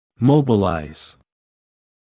[‘məubəlaɪz][‘моубэлайз]мобилизировать; делать подвижным (в т.ч. о костных фрагментах при операции)